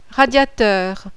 RADIATEUR.wav